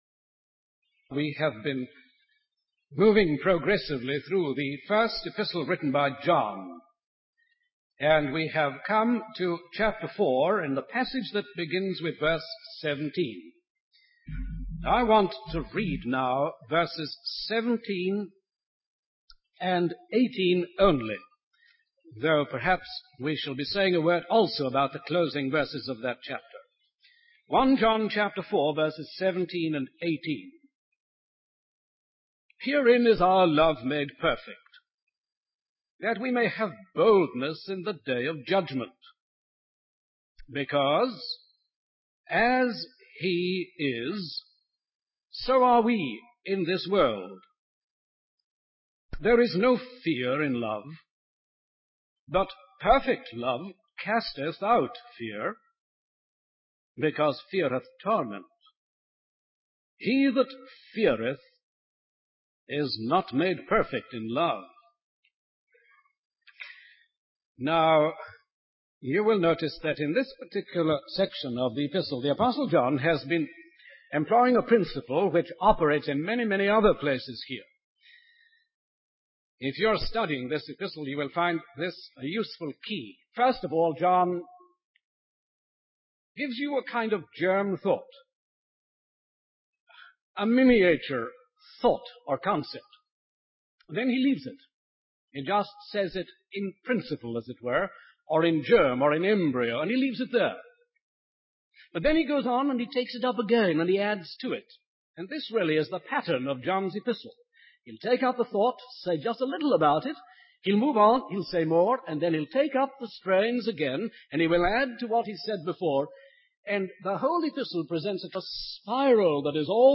In this sermon, the preacher focuses on 1 John chapter 4 verses 17 and 18. He emphasizes that our love is made perfect through our relationship with God. He explains that because God is love, there is no fear in love, and perfect love casts out fear.